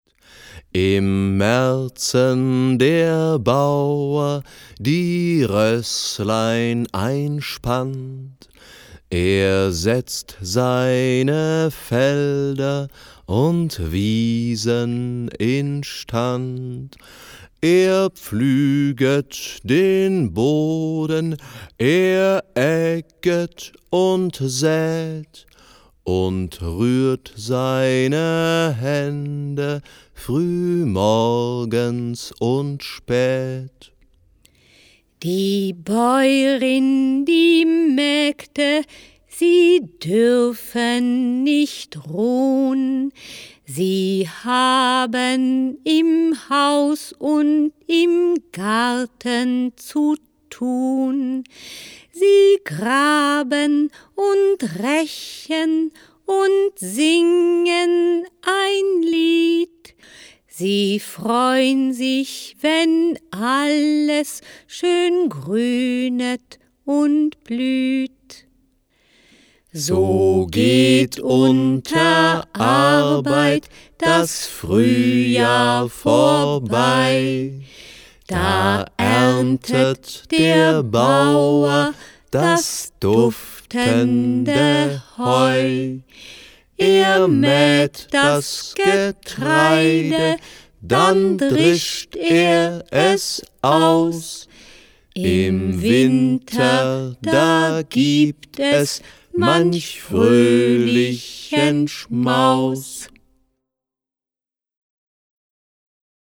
In diesem Buch für Menschen mit Demenz können Volkslieder auf Knopfdruck abgespielt werden.
Schlagworte Aktivierung • Alzheimer • Alzheimer Beschäftigung • Alzheimer Geschenk • Alzheimer Geschenkbuch • Alzheimer Musik • Alzheimer Spiele • Bilderbuch • Biographiearbeit • Bücher für alzheimerkranke Menschen • Bücher für Alzheimer Patienten • Bücher für demenzkranke Menschen • Bücher für Demenz Patienten • Dementenarbeit • Demenz • Demenz Beschäftigung • Demenz Geschenk • Demenz Geschenkbuch • Demenz Musik • Demenz Spiele • Frühlingslieder • Hörbuch • Liederbuch • Musiktherapie • Soundbuch • Soundchip • Tonleistenbuch • Volkslieder